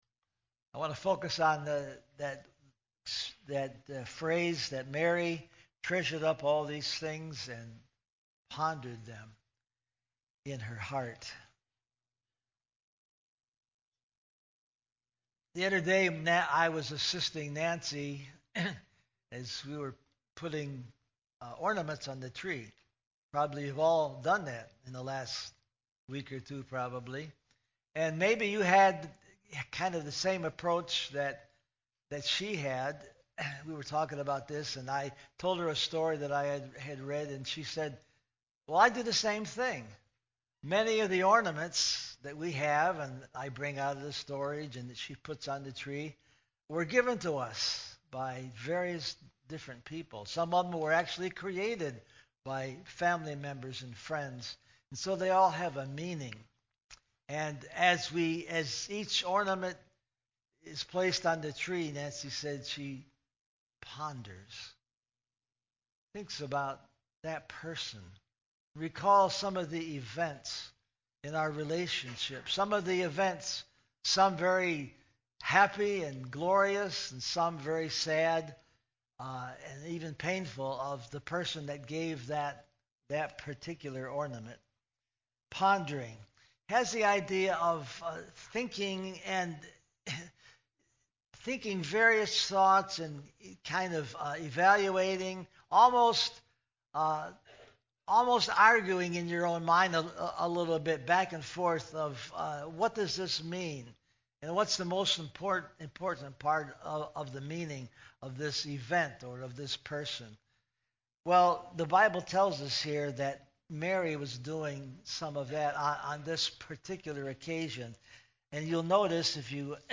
December 24, 2021- Christmas Eve Service